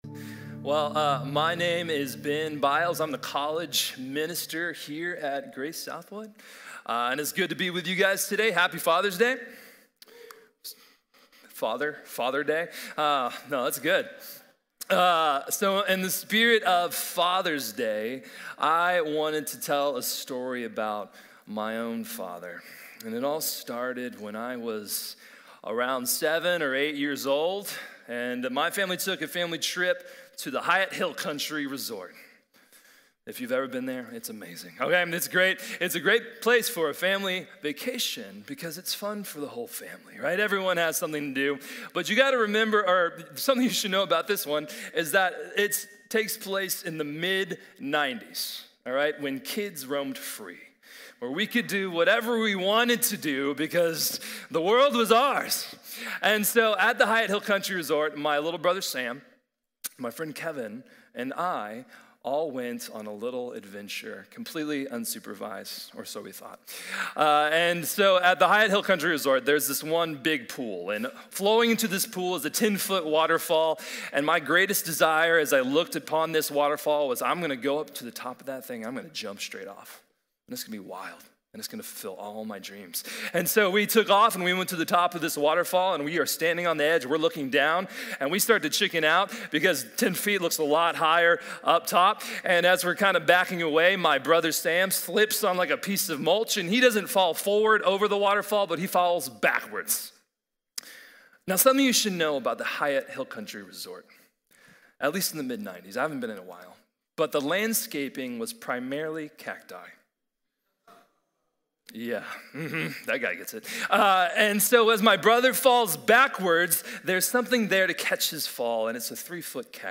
Joshua’s Faithful Obedience | Sermon | Grace Bible Church